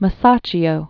(mə-sächē-ō, mä-sätchō) Originally Tommaso di Mone. 1401-1428.